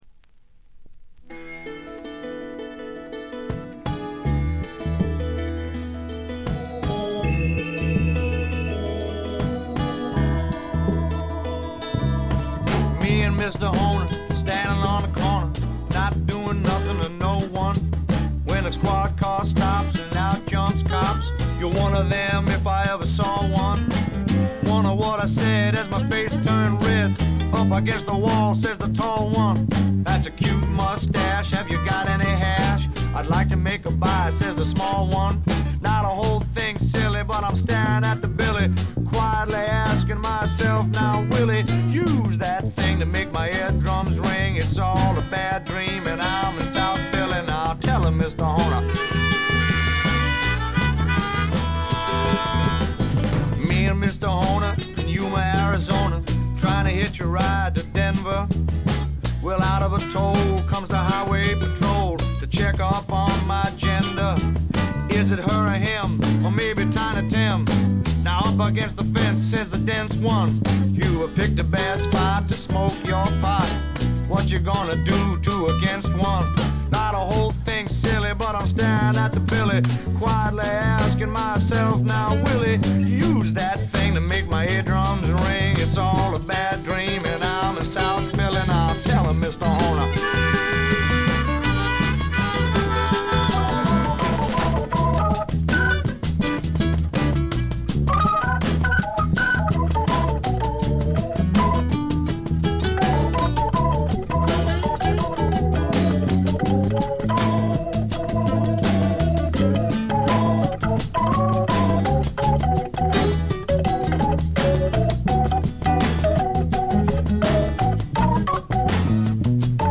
ほとんどノイズ感無し。
けっこう色々な音が鳴っていて厚みがあるのと音そのものがすごくいいコードです。
試聴曲は現品からの取り込み音源です。
Vocals, Blues Harp, Keyboards
Guitar
Piano
Bass
Drums